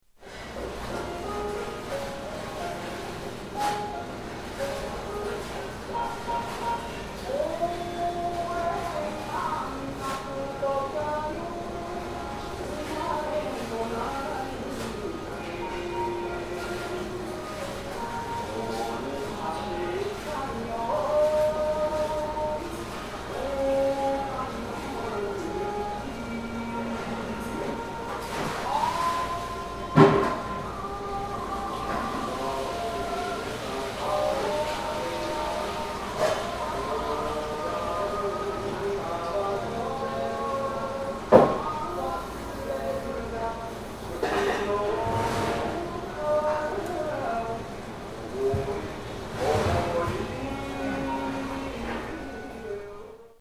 Food shop in Taipei